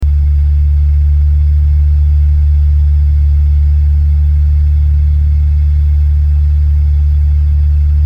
Fan 04
Fan_04.mp3